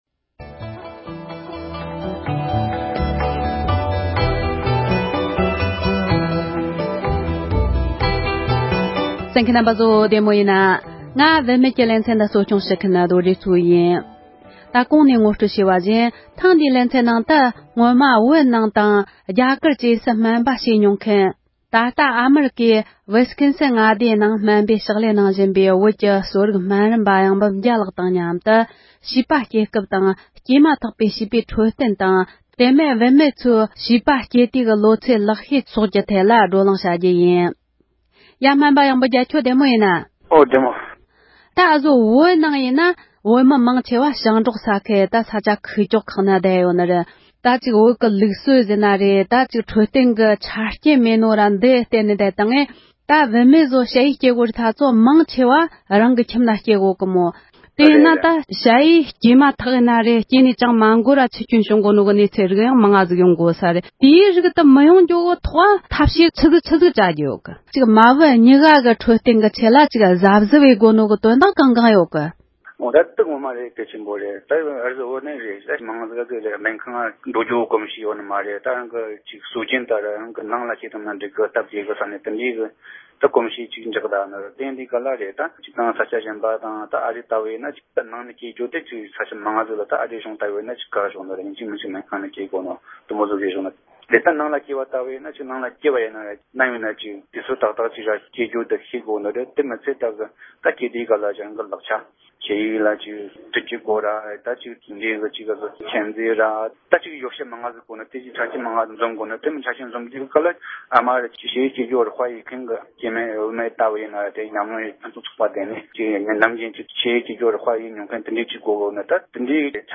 བུད་མེད་དང་ཕྲུ་གུའི་འཕྲོད་བསྟེན་དང་འབྲེལ་བའི་གནས་ཚུལ་ཁག་ཅིག་གི་ཐད་བགྲོ་གླེང༌།